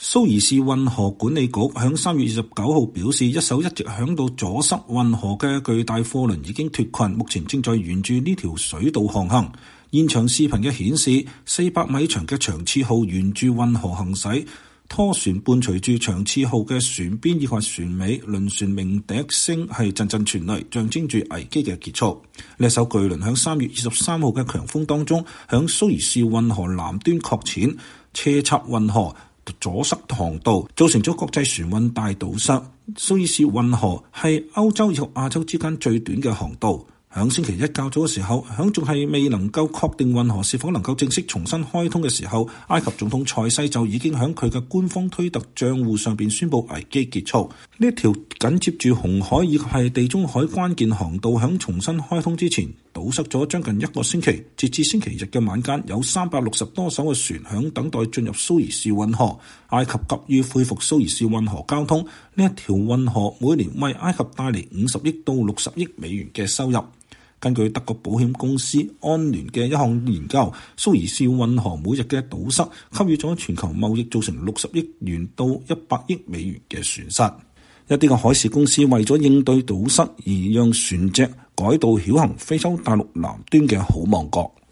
輪船鳴笛聲陣陣傳來，象徵著危機的結束。